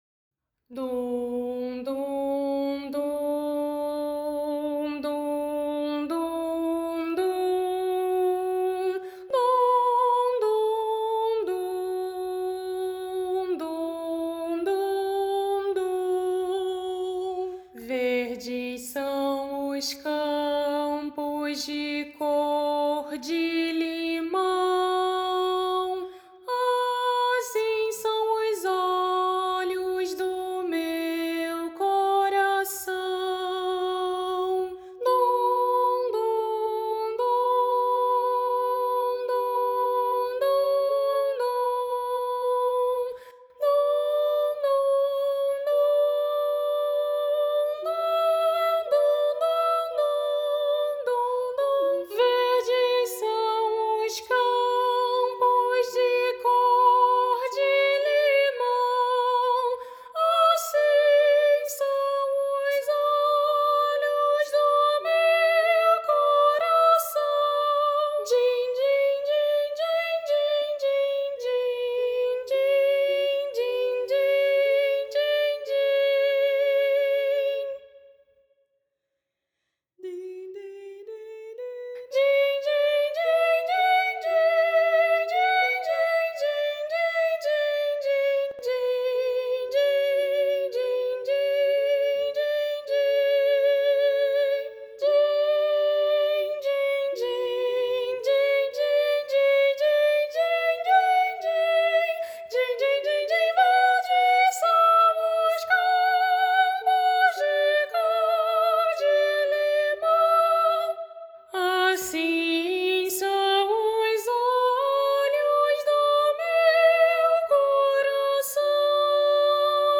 para coro infantil a três vozes
Voz Guia